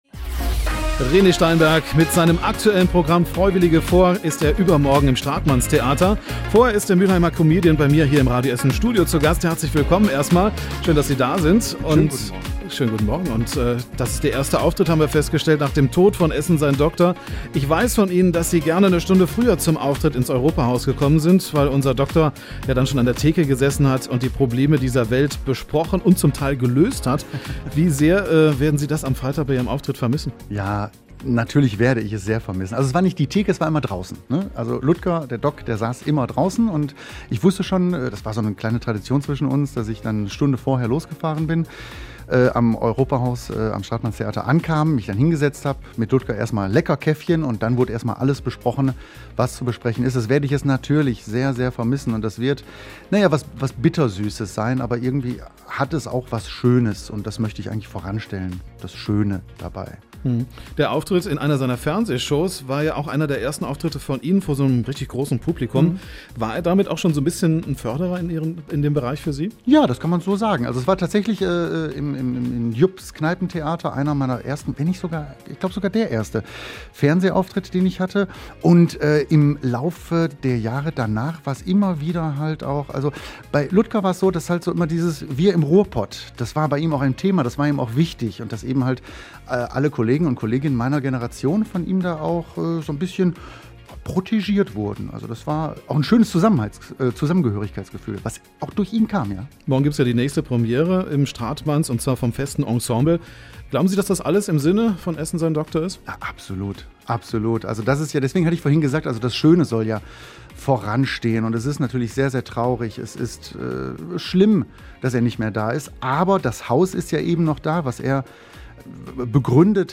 Comedian Rene Steinberg zu Gast bei Radio Essen - Radio Essen
Wir müssen mehr Humor wagen! Das sagt unser Studiogast René Steinberg.